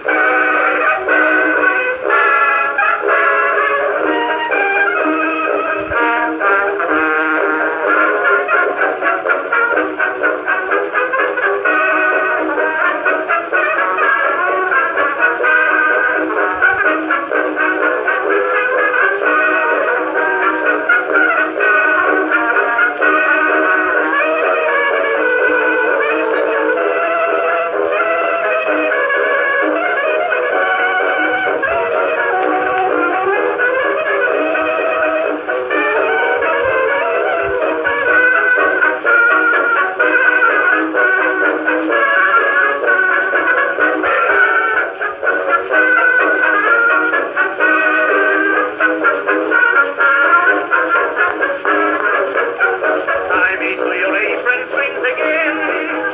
With its quiet running direct drive motor and airtight horn assembly, the Edison Opera was indeed Edison's supreme achievement in the acoustic reproduction of sound. The wooden cygnet type horn assembly develops unanticipated fidelity and volume for its size.